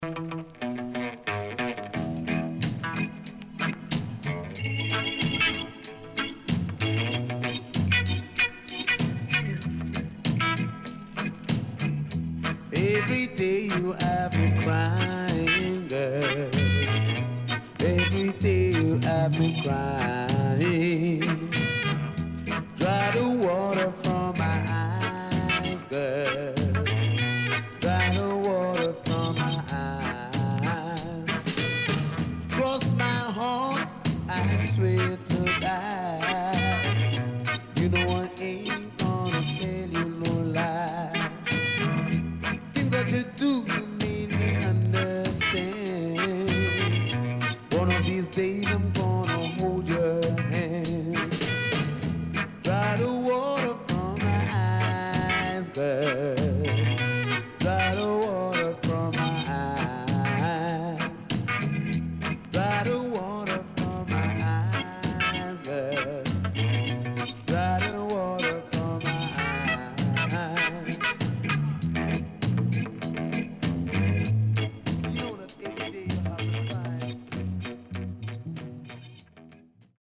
SIDE B:所々チリノイズがあり、少しプチノイズ入ります。